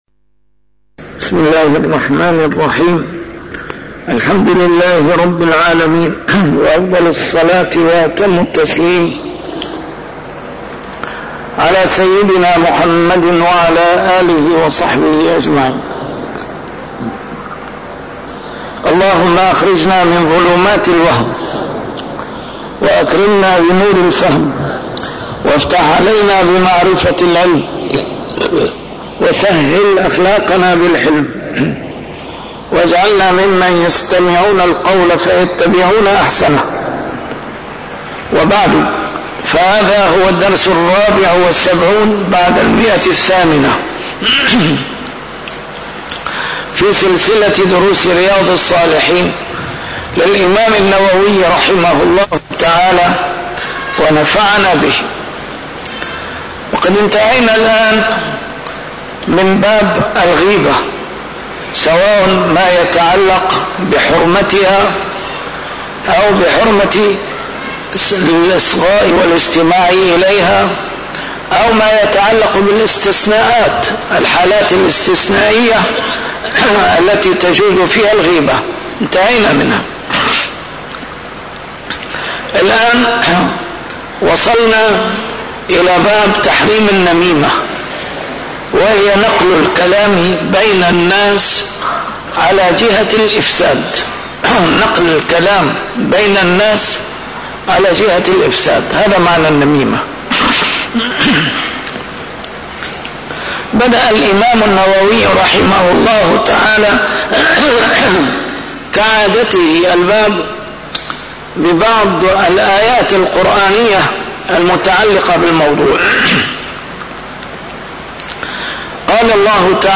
A MARTYR SCHOLAR: IMAM MUHAMMAD SAEED RAMADAN AL-BOUTI - الدروس العلمية - شرح كتاب رياض الصالحين - 874- شرح رياض الصالحين: تحريم النميمة